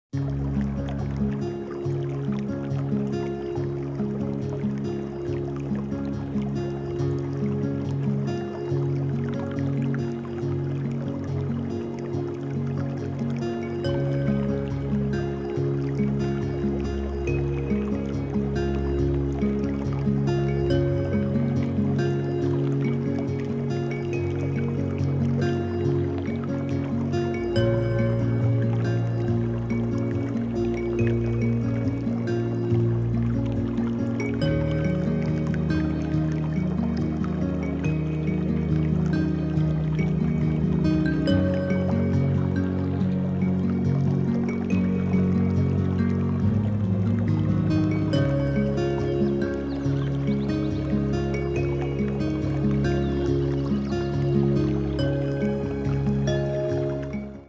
Wasserfall-Musik-1min.mp3